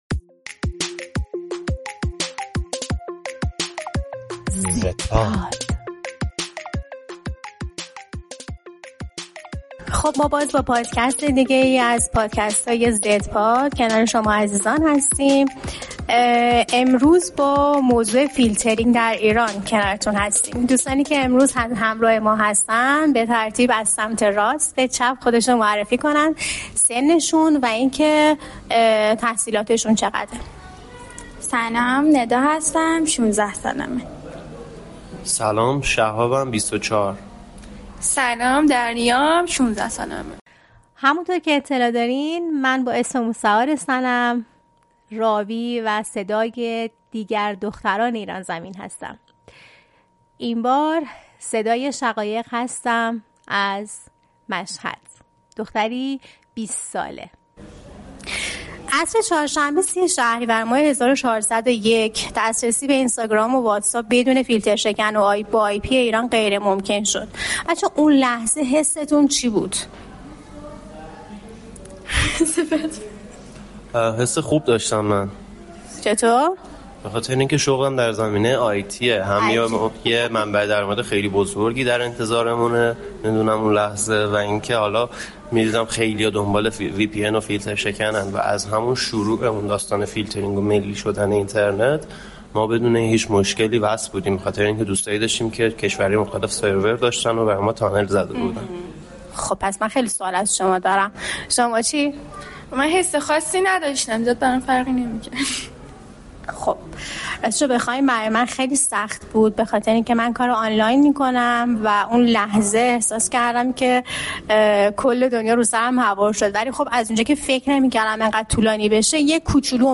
در این قسمت پادکست «زدپاد» گروهی از جوانان نسل زد در این مورد صحبت می‌کنند.